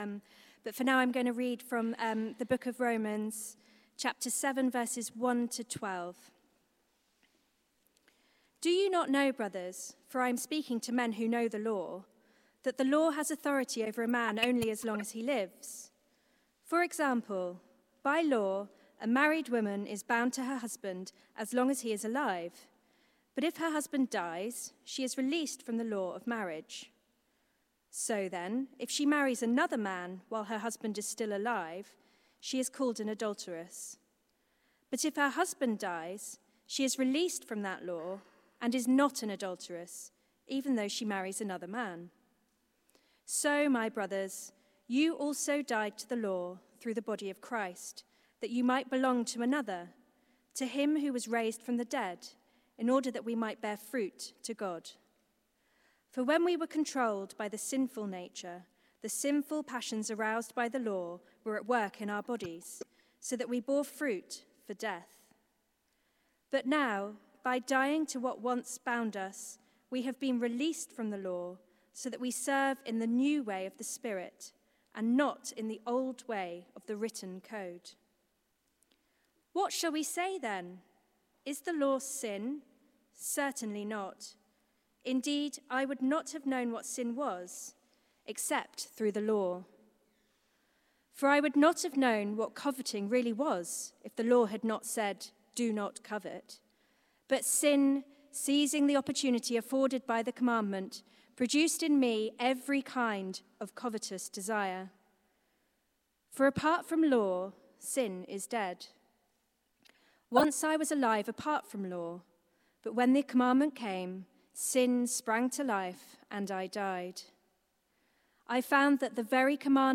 From Slavery to Salvation: Romans 6 - 8 Theme: New Life in the Spirit Sermon